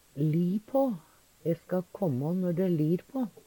li på - Numedalsmål (en-US)